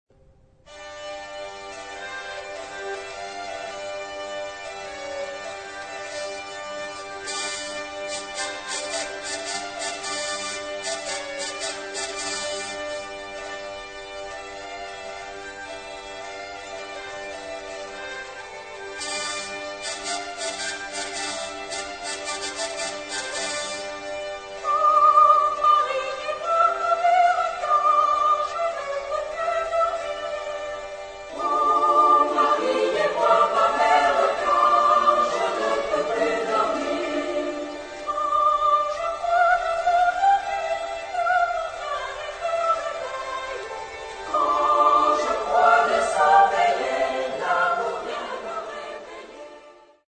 Genre-Stil-Form: weltlich ; Volkstümlich
Charakter des Stückes: humorvoll
Chorgattung: SSAA  (4 Frauenchor Stimmen )
Solisten: Soprano (1) / Alto (1)  (2 Solist(en))
Instrumente: Drehleier (ad lib)
Tonart(en): d-moll